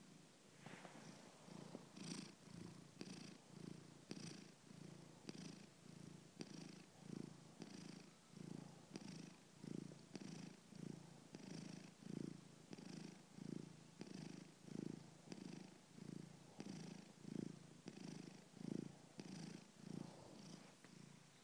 Roxy purring 2